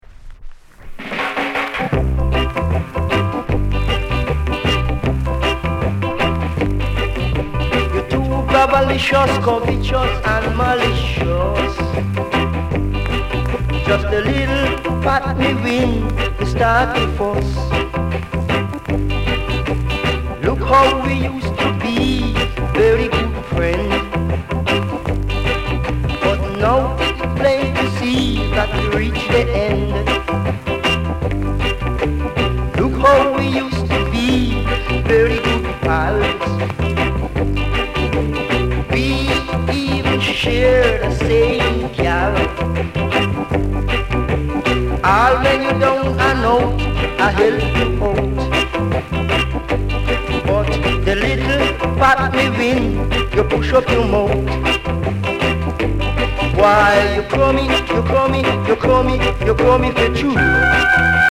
Sound Condition A SIDE VG(OK)